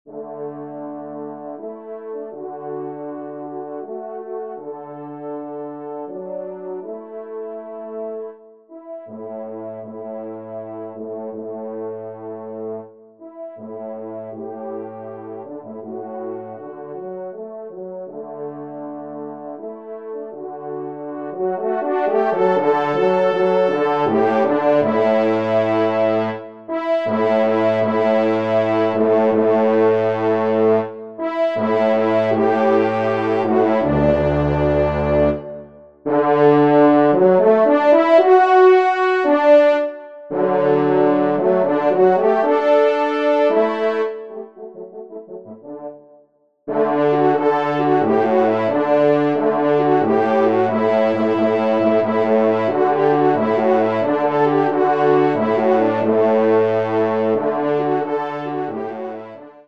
Genre :  Divertissement pour quatre Trompes ou Cors en Ré
Pupitre 4°Trompe